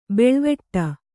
♪ beḷveṭṭa